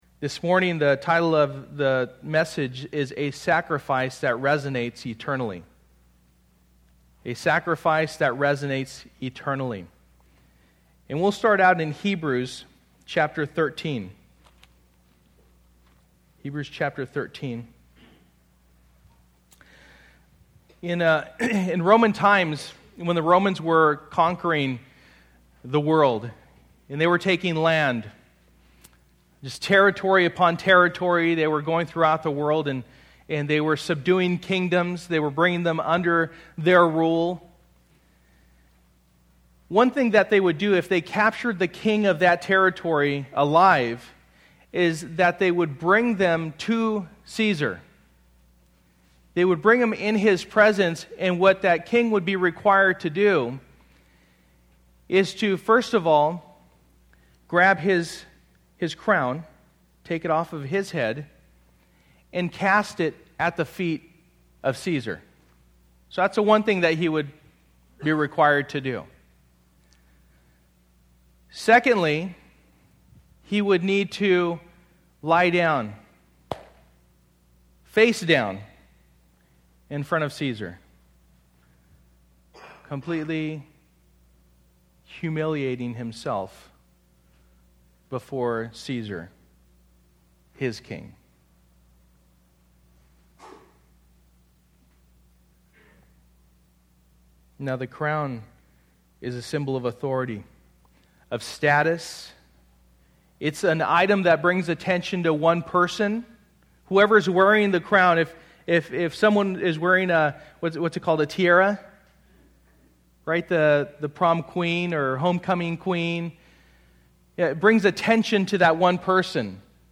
Passage: Hebrews 13:15 Service: Sunday Morning